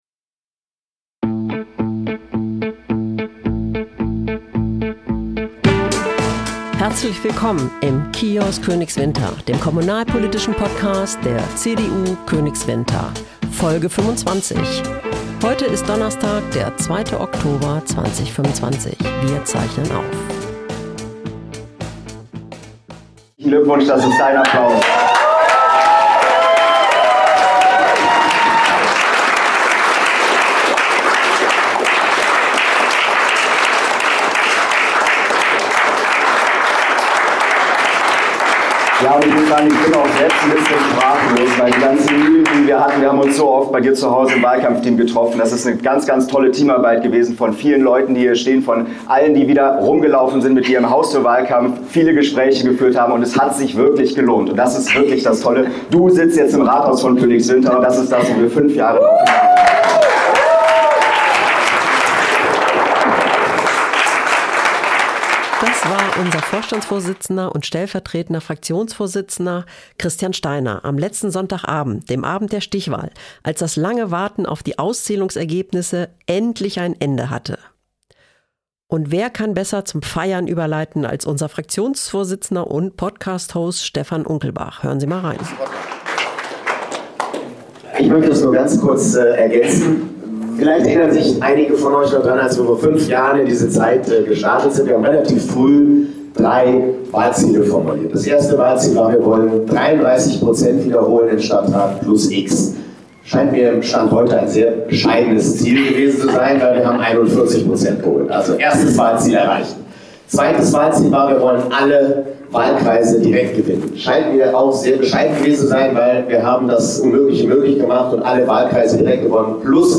Was es bis dahin alles zu tun gibt, erzählt uns die frisch gewählte am Telefon. Aber zuvor nehmen wir Sie nochmal mit in die Stimmung am Abend der Stichwahl.